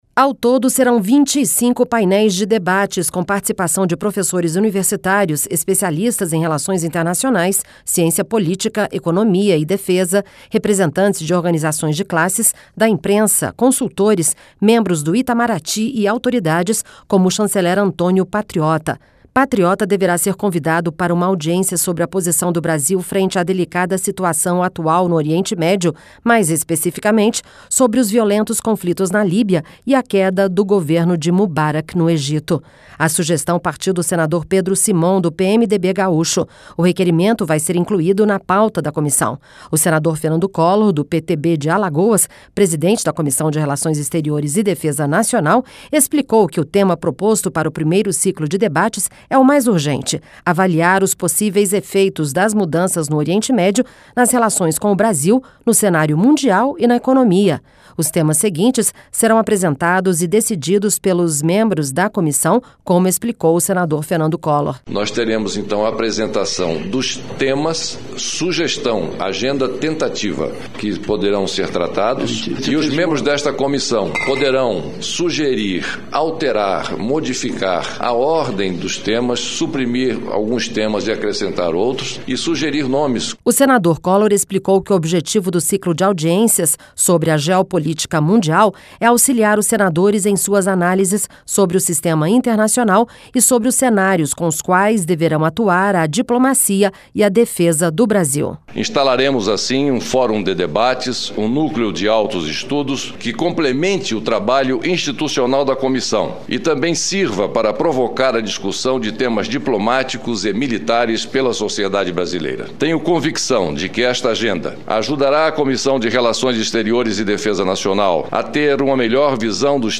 Situação do mundo árabe deve ser tema de ciclo de debates na CRE LOC: AS MUDANÇAS NO EGITO